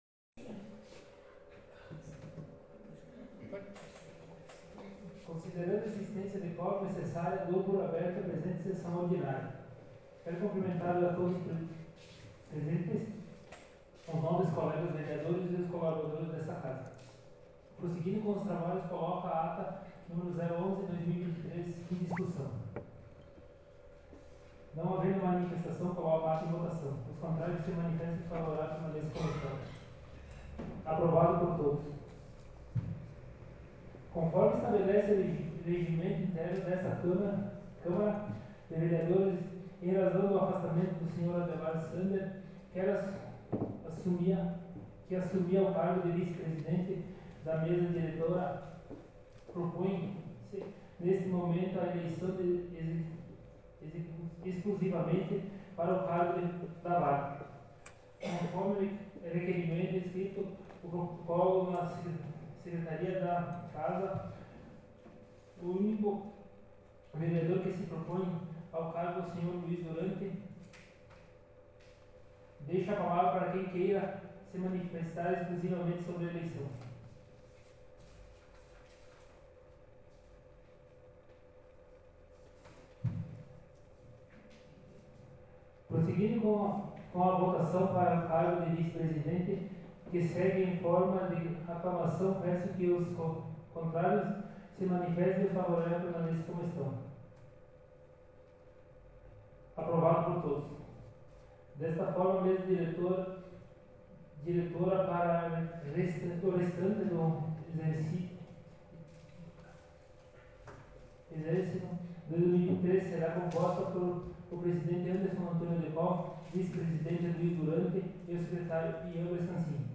Em anexo arquivo de gravação em áudio da Sessão Ordinária realizada na Câmara de Vereadores de Vanini na data de 24/07/2023.